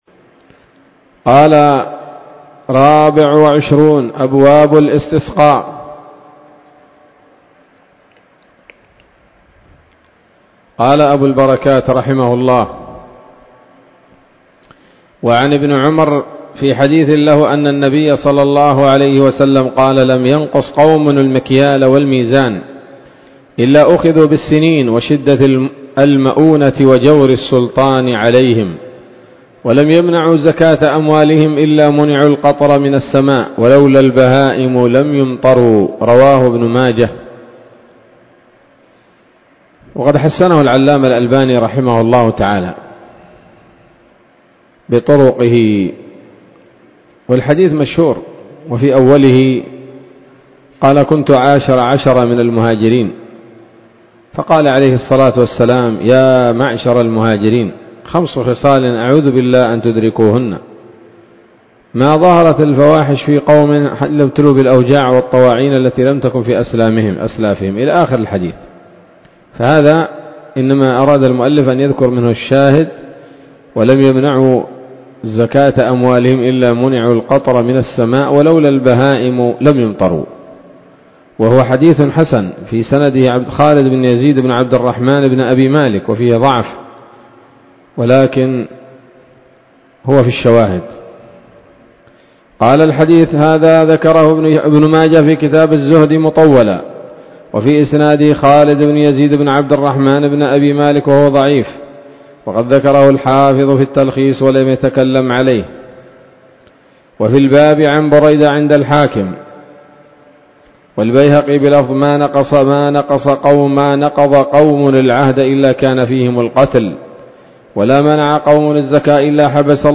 الدرس الأول من ‌‌‌‌كتاب الاستسقاء من نيل الأوطار